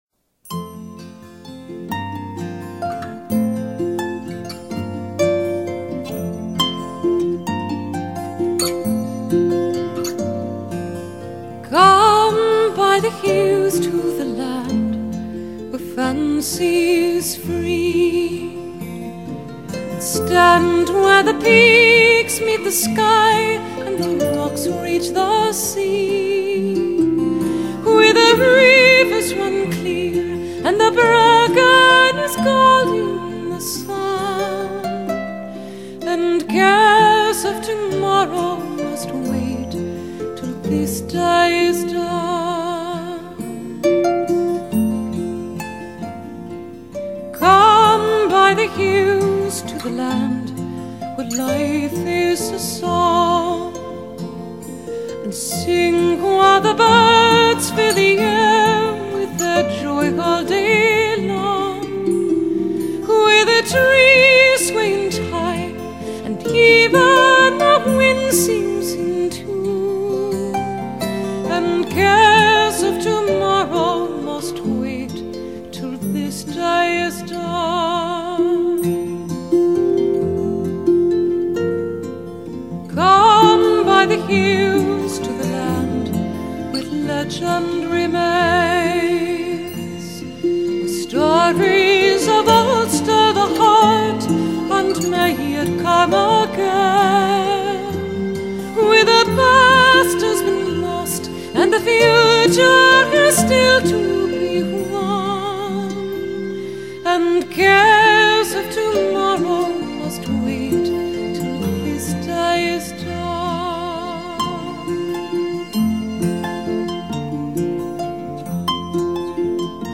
音乐类别：爵士人声
一切的一切，目的就是为了表现凯尔特音乐特有的旋律和味道。
音乐的旋律固然极其古朴而悠扬，但是歌词听来都具有爱尔兰特有的韵味。